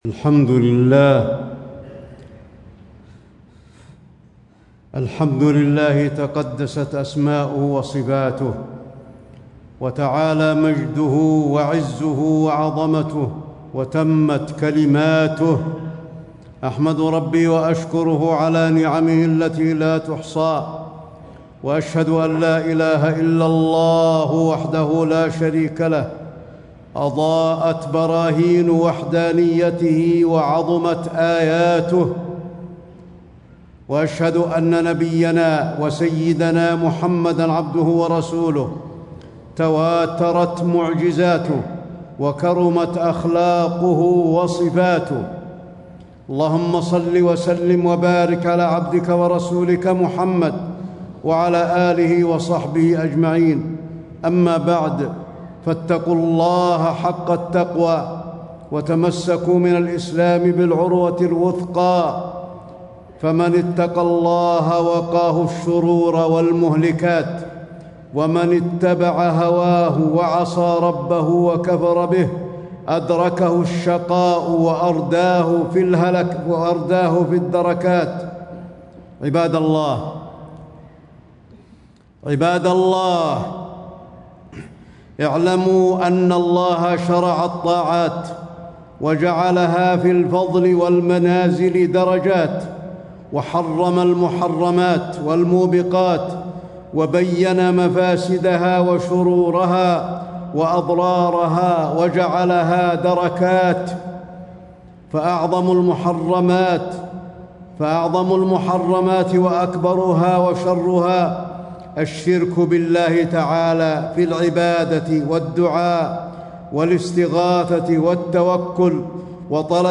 تاريخ النشر ٢١ محرم ١٤٣٦ هـ المكان: المسجد النبوي الشيخ: فضيلة الشيخ د. علي بن عبدالرحمن الحذيفي فضيلة الشيخ د. علي بن عبدالرحمن الحذيفي حرمة الدماء المعصومة